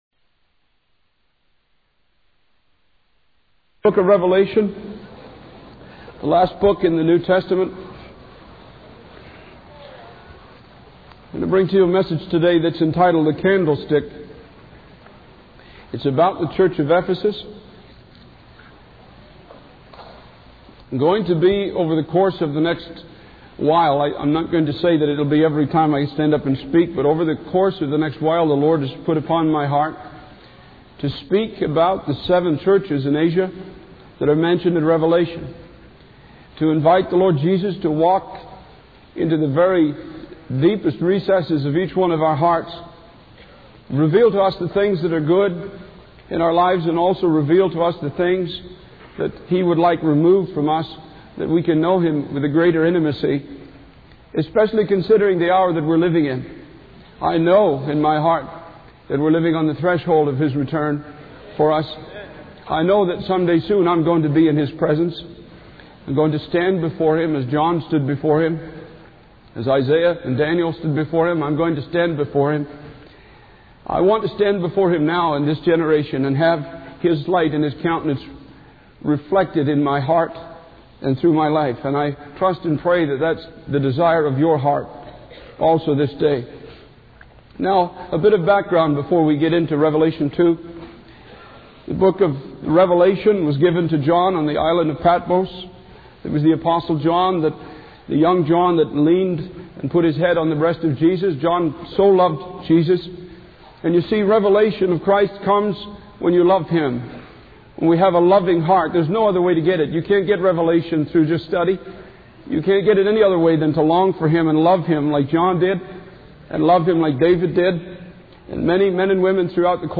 In this sermon, the preacher warns the audience about the danger of losing their love relationship with God. He emphasizes the importance of remembering where they have fallen from and turning back to God. The preacher uses the analogy of a marriage ceremony to illustrate the initial love and cherishing that should be present in their relationship with God.